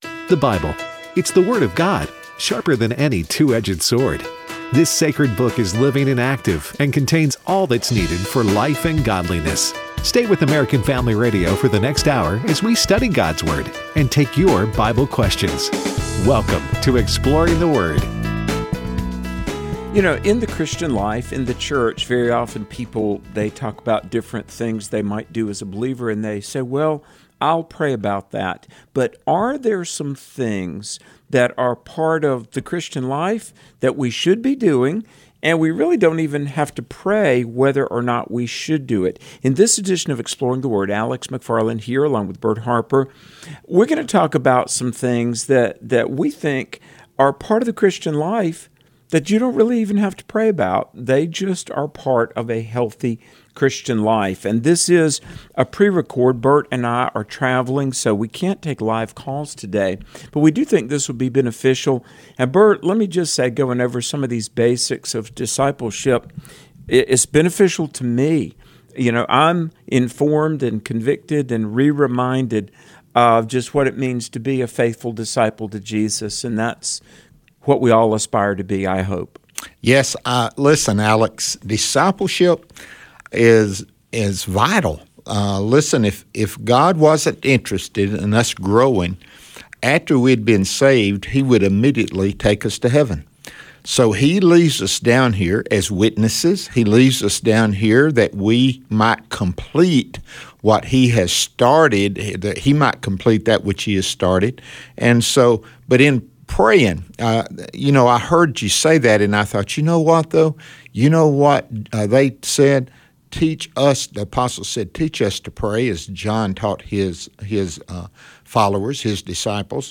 Things You Don"t Have To Pray About | 02/17/25 | Prerecorded